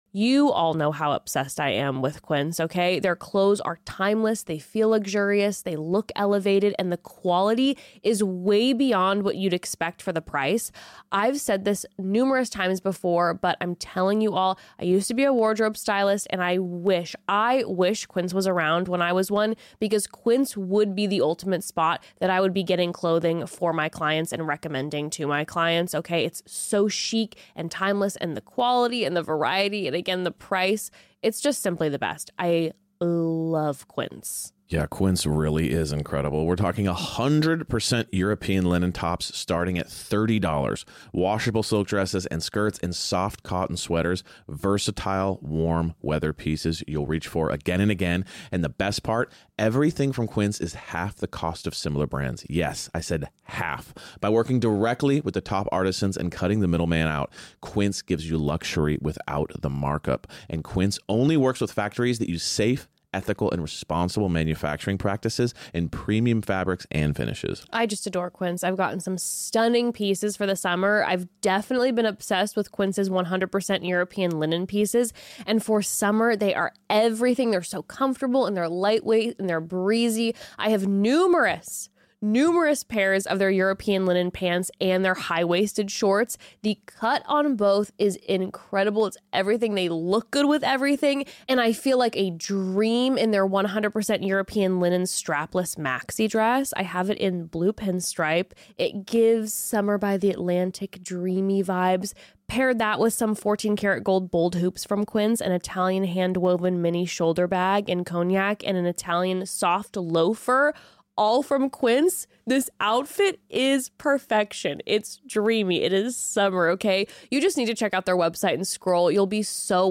Today The Bros are in the studio and they go on a wild ride of topics from choirs